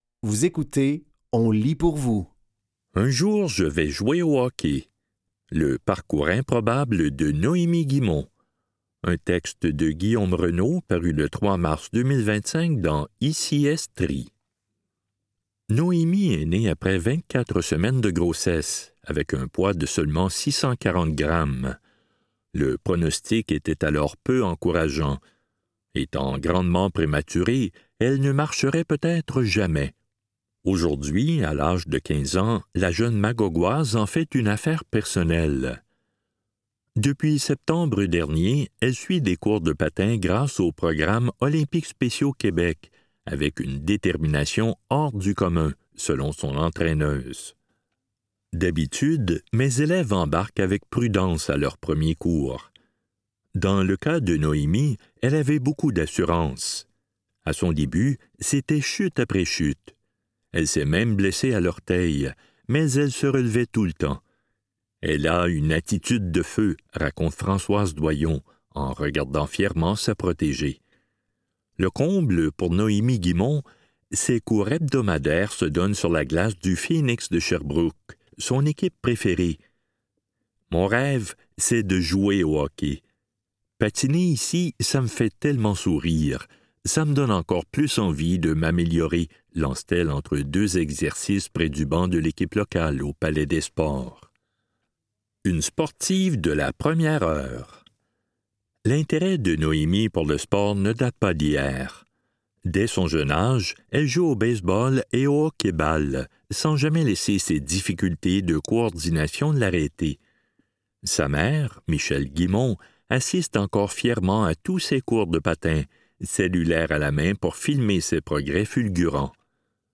Dans cet épisode de On lit pour vous, nous vous offrons une sélection de textes tirés des médias suivants : ICI Estrie, Radio-Canada, Le Manic et La Presse.